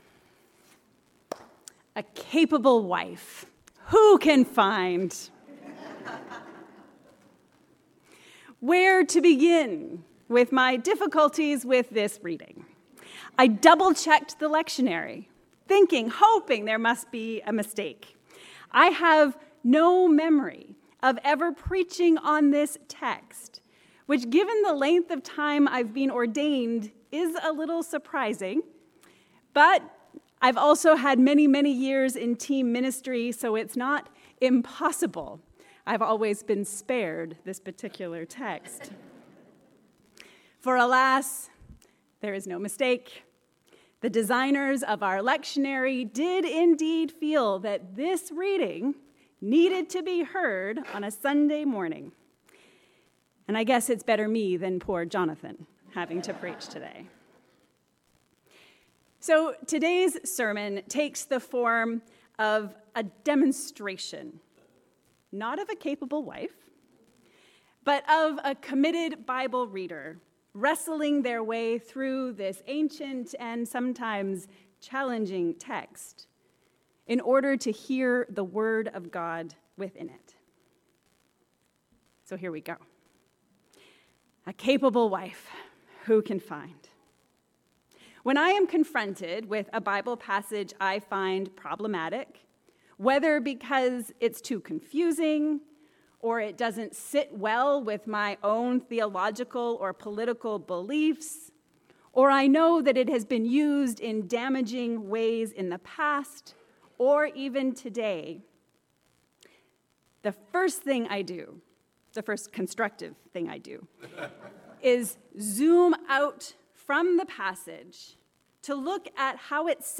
A capable wife who can find? Or, how to wrestle with the Bible. A sermon on Proverbs 31